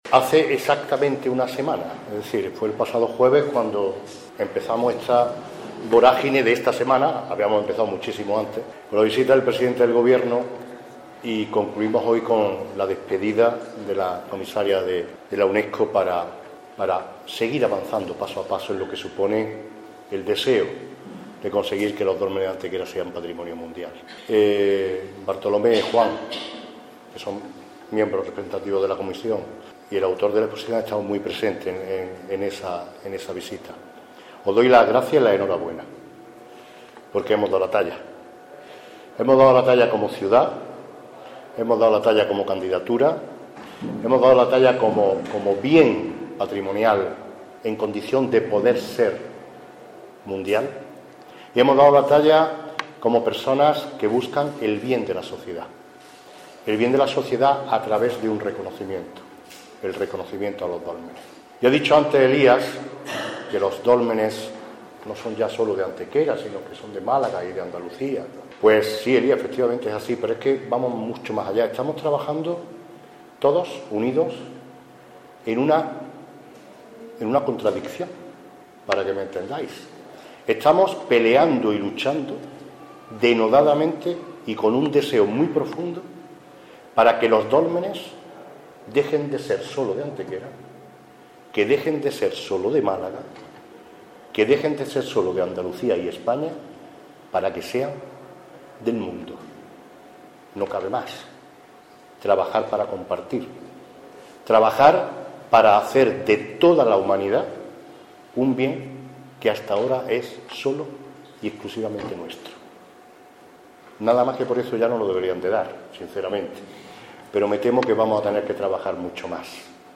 Inauguración de la exposición Sitio de los Dólmenes de Antequera en el MAD
También se incluye corte de audio con la intervención del alcalde Manolo Barón.